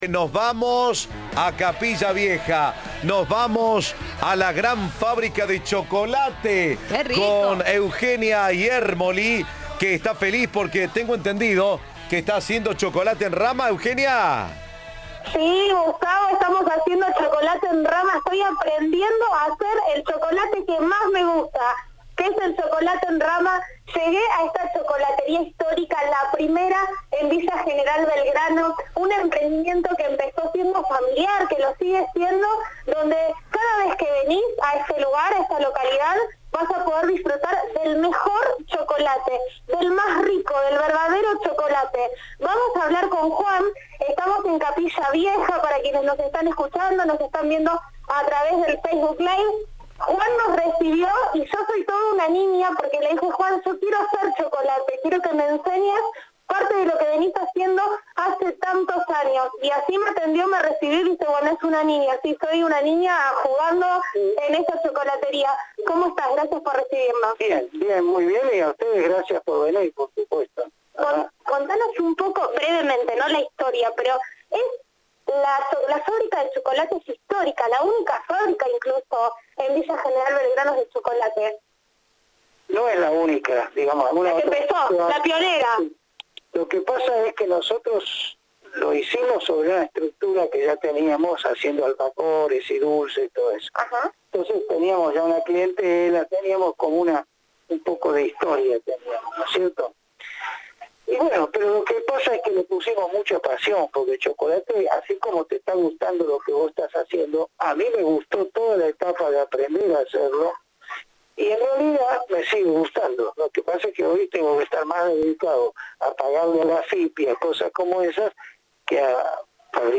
La fábrica está ubicada en Villa General Belgrano y ofrece todo tipo de productos dulces. Reviví el Facebook Live y mirá cómo se hace el chocolate en rama.